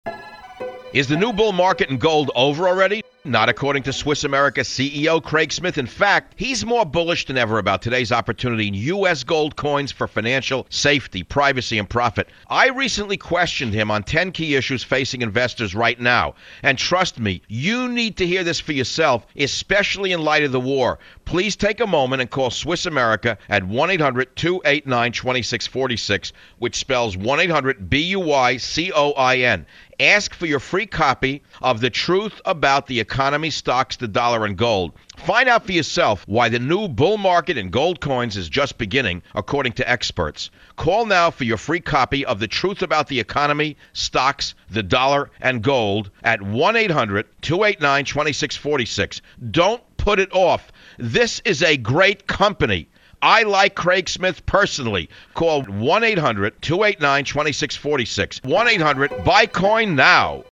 (Listen to what
Michael Savage says about this interview)